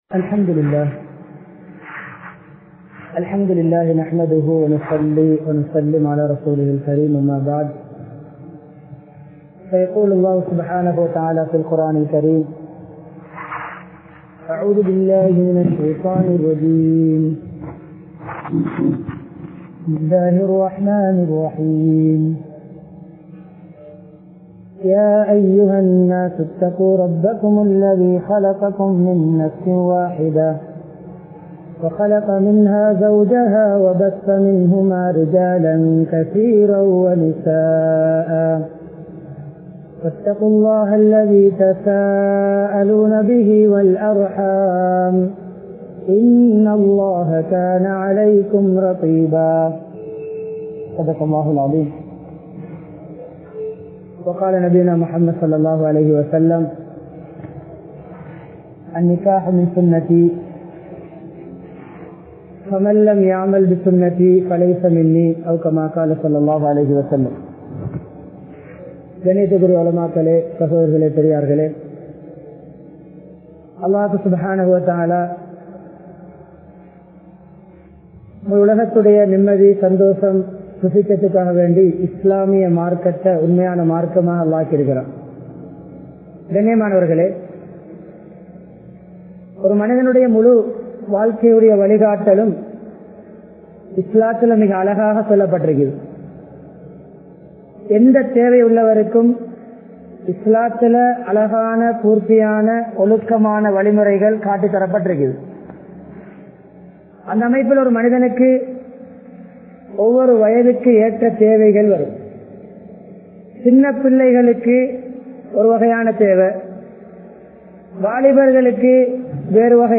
Islam Angeehariththa Nikkah (இஸ்லாம் அங்கீகரித்த நிக்காஹ்) | Audio Bayans | All Ceylon Muslim Youth Community | Addalaichenai